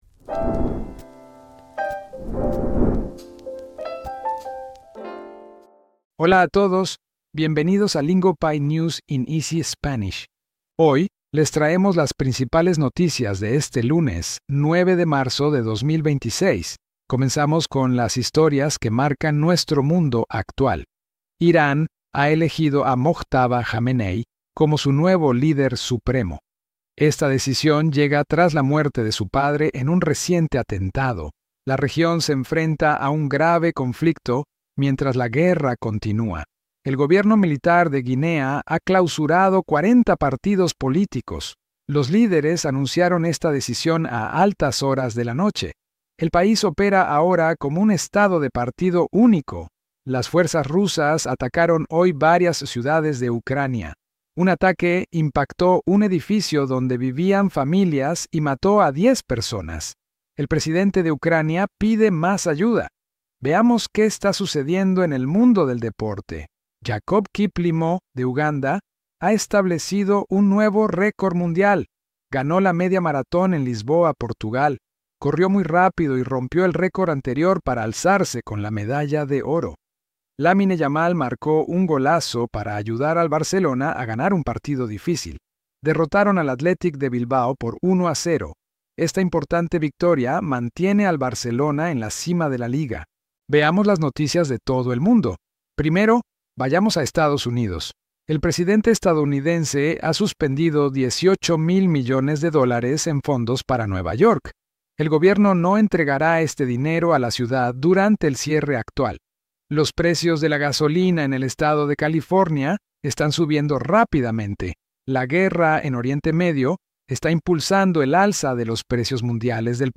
Lingopie’s News in Easy Spanish gives you real-world Spanish listening practice through today’s biggest global headlines. We deliver each story in clear, beginner-friendly Spanish, so you can follow along without rewinding 17 times.